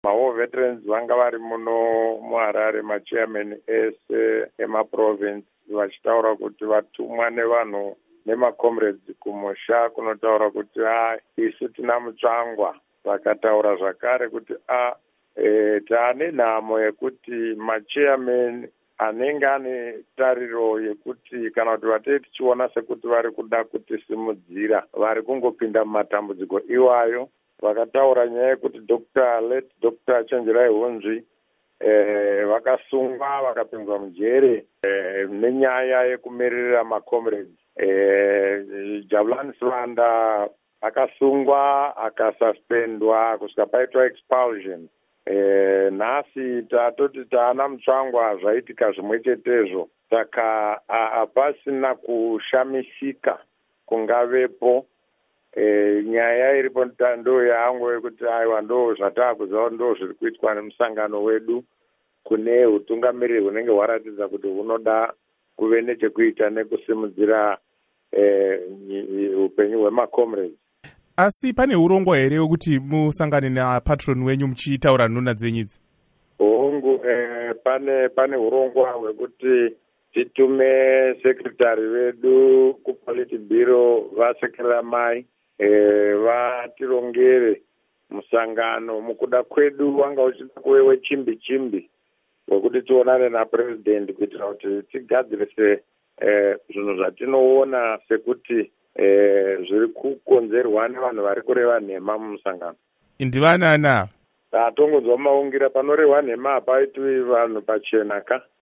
Hurukuro naVaVictor Matemadanda